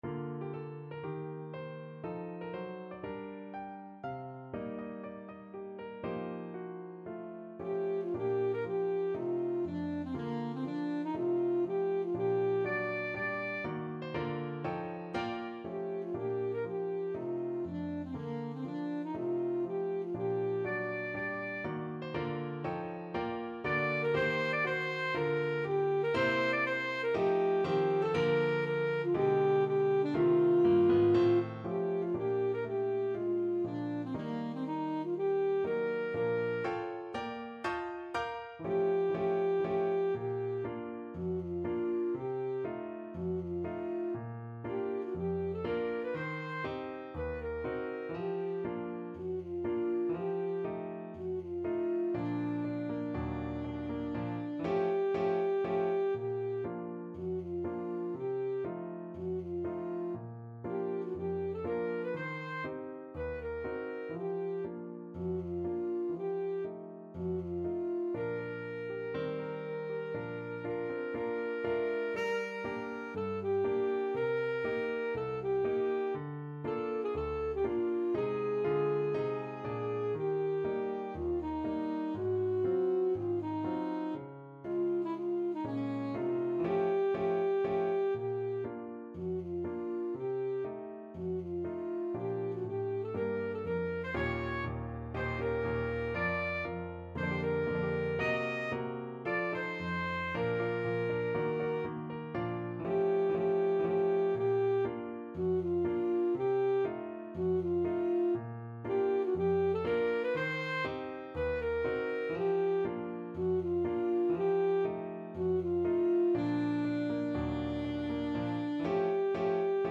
Alto Saxophone version
~ = 120 Moderato
4/4 (View more 4/4 Music)
Bb4-Eb6
Saxophone  (View more Intermediate Saxophone Music)
Classical (View more Classical Saxophone Music)